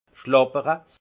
Haut Rhin schlàppere Français boire comme les vaches
Ville Prononciation 68 Munster